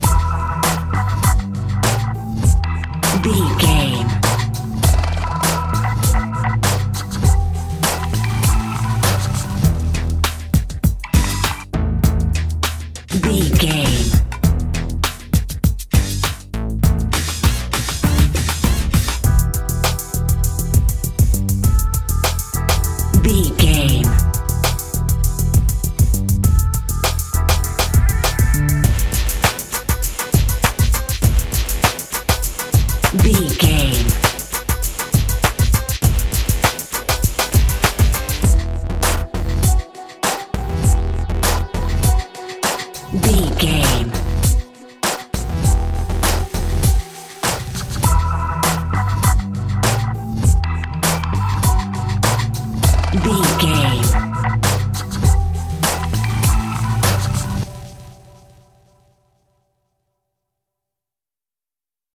Aeolian/Minor
synthesiser
drum machine
hip hop
Funk
neo soul
acid jazz
confident
energetic
bouncy
funky